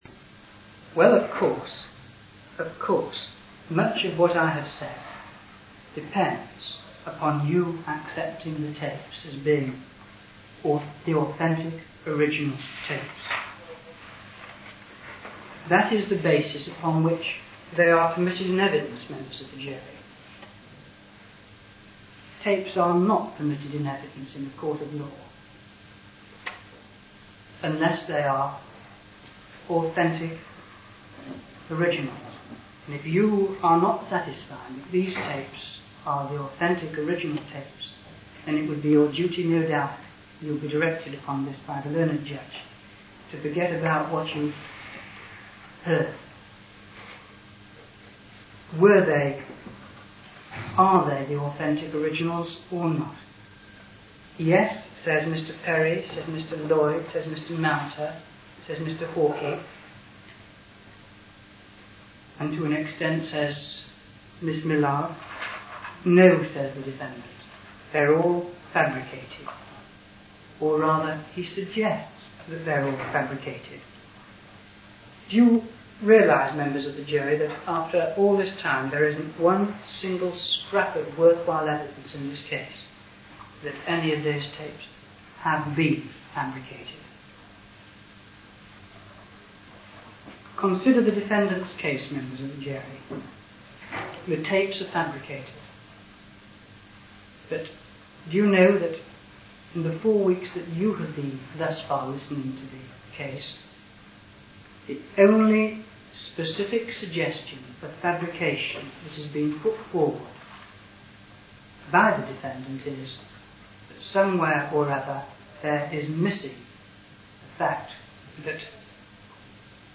Listen to a recording of Rivlin�s closing speech